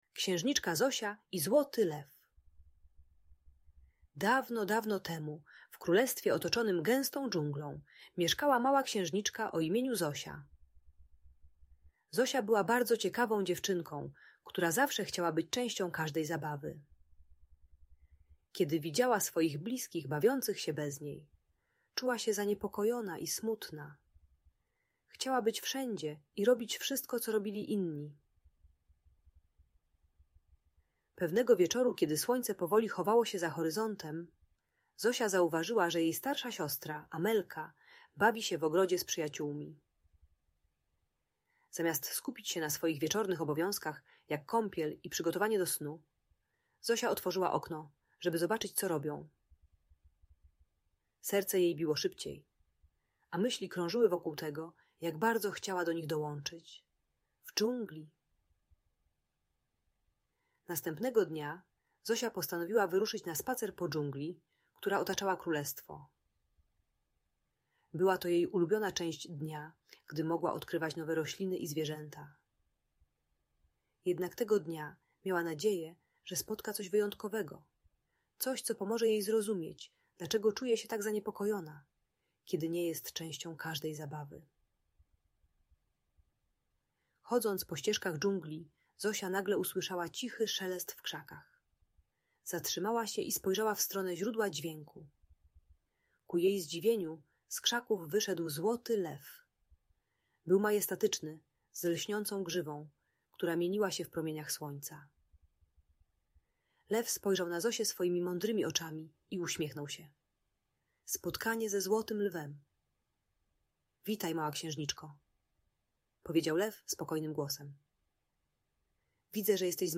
Księżniczka Zosia i Złoty Lew - Audiobajka dla dzieci